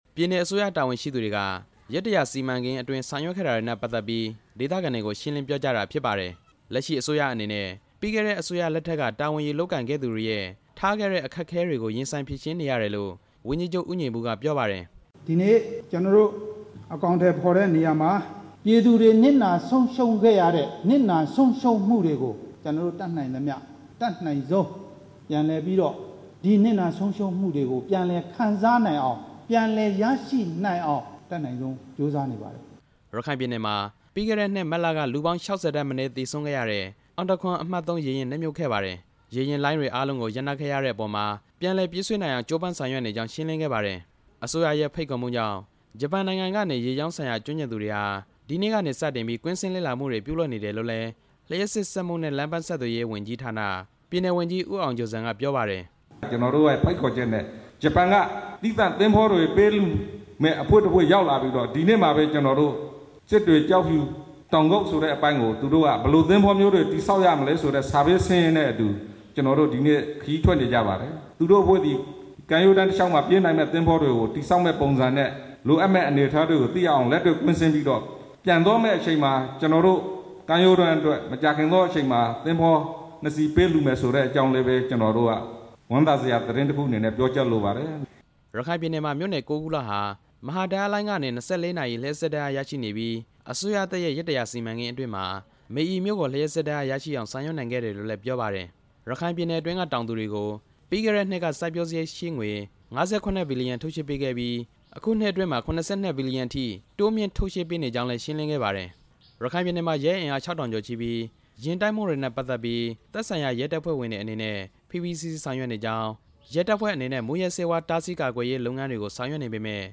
ဒီကနေ့ ရခိုင်ပြည်နယ် စစ်တွေမြို့ ဦးဥတ္တမခန်းမမှာကျင်းပတဲ့ ပြည်နယ်အစိုးရရဲ့ ရက်တစ်ရာစီမံချက် ဆောင်ရွက် မှု အခြေအနေတွေကို ရှင်းလင်းတဲ့အခမ်းအနားမှာ ဝန်ကြီးချုပ် ဦးညီပုက ပြောကြားလိုက်တာဖြစ်ပါတယ်။